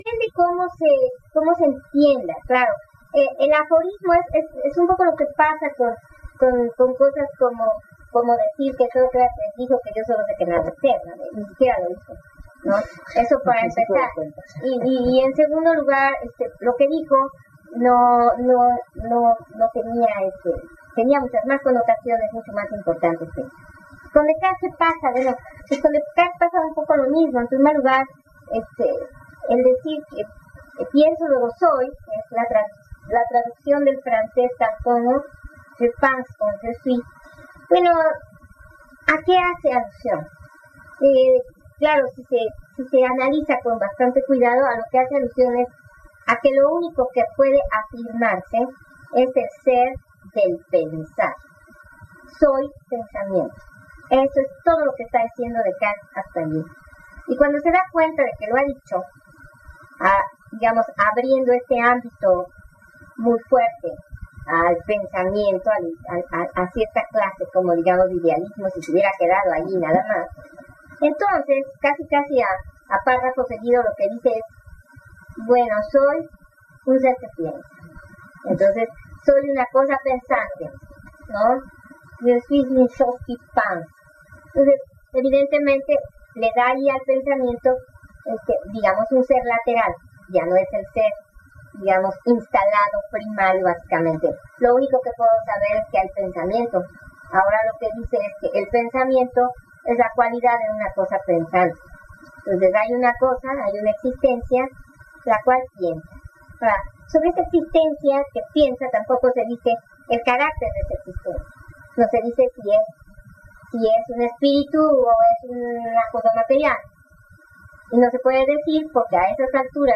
Entrevista: La filosofia cartesiana: una ancla a la razon